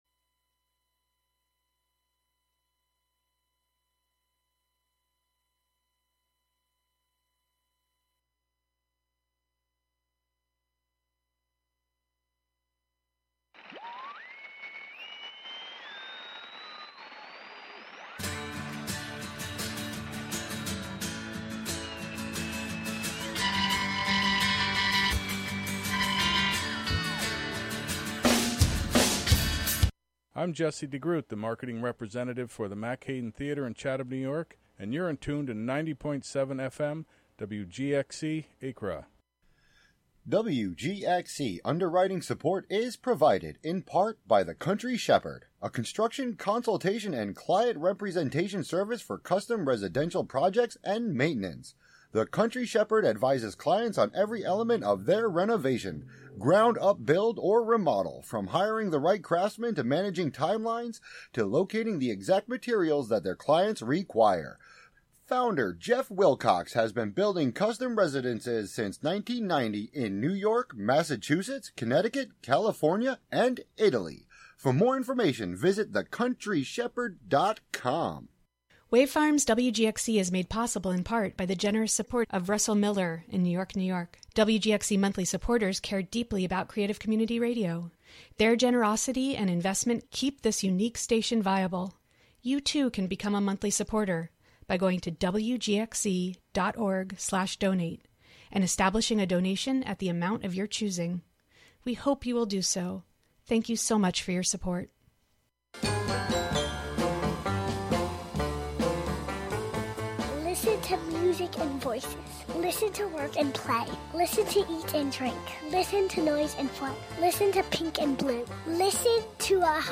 Monthly excursions into music, soundscape, audio document, and spoken word, inspired by the wide world of performance. Live from Ulster County.